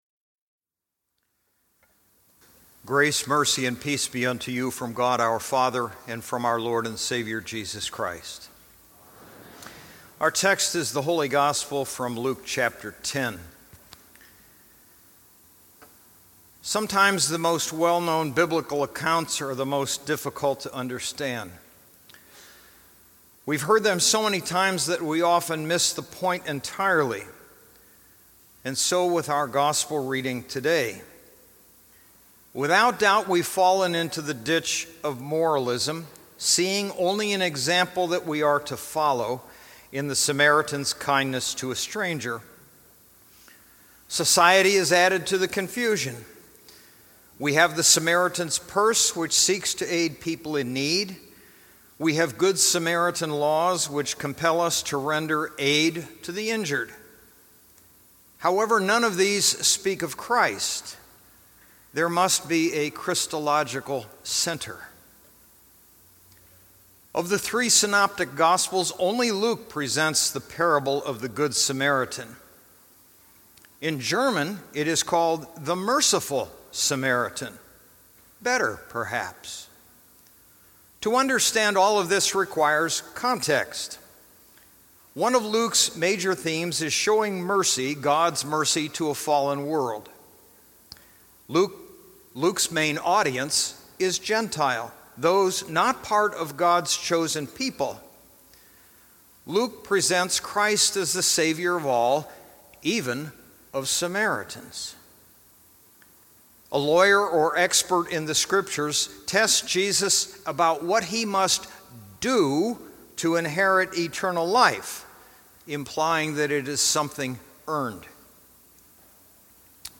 The Thirteenth Sunday after Trinity